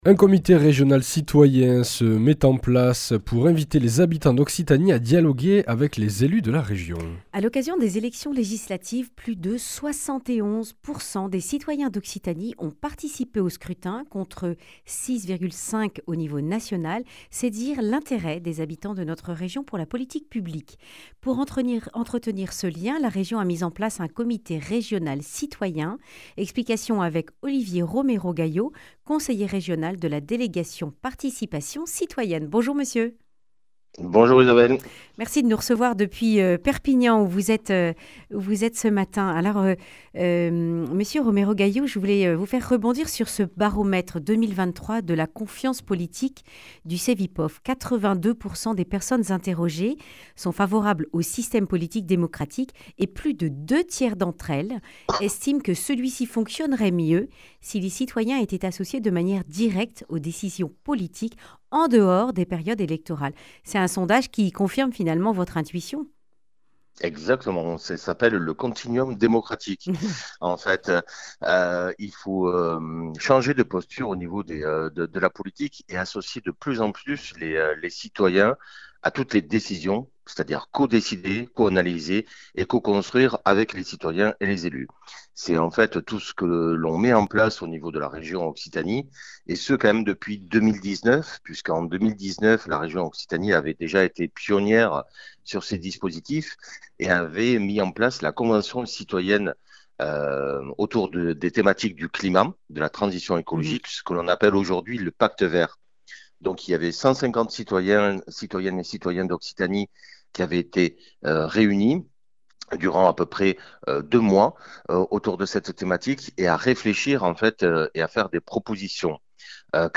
Explications d’Olivier Romero Gayo, conseiller régional de la délégation "Participation citoyenne".
Le grand entretien